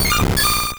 Cri de Leuphorie dans Pokémon Or et Argent.